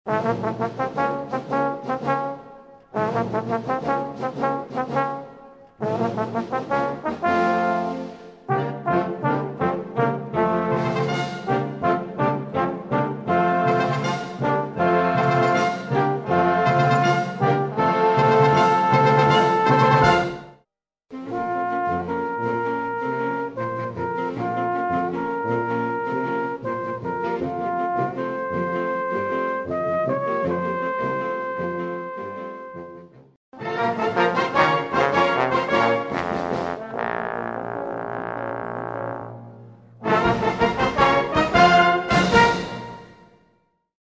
Symphonic Music for Wind Orchestras
Solo Instruments with Symphonic Wind Orchestra Accompaniment